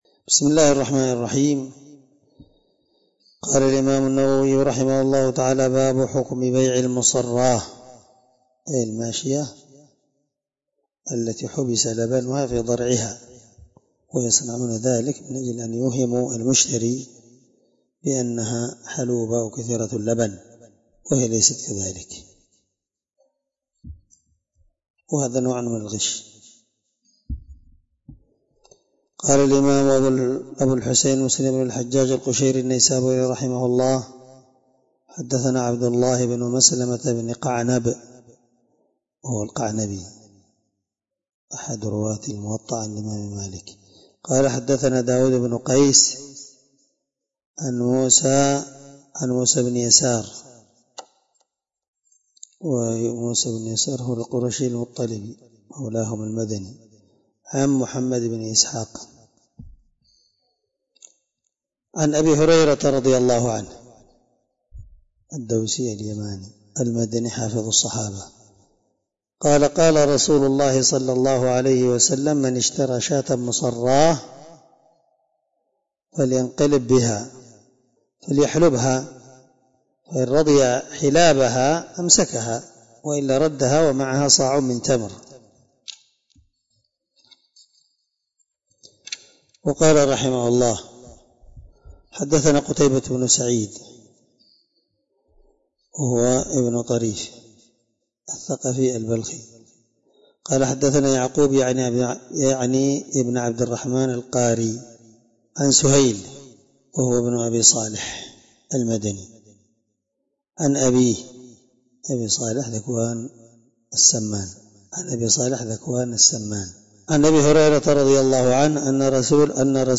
الدرس8من شرح كتاب البيوع حديث رقم(1524) من صحيح مسلم